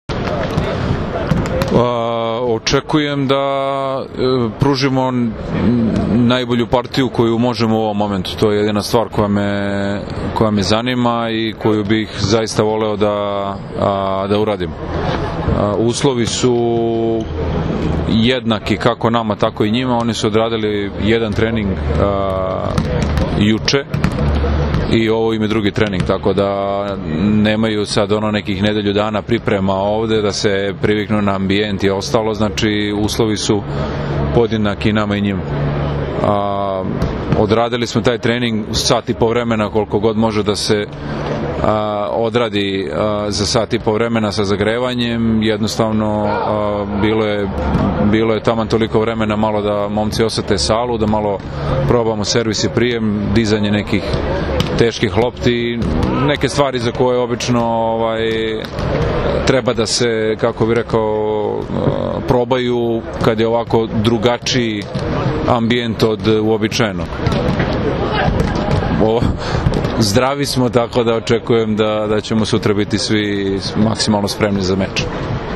IZJAVA NIKOLE GRBIĆA